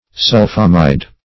Meaning of sulphamide. sulphamide synonyms, pronunciation, spelling and more from Free Dictionary.
sulphamide.mp3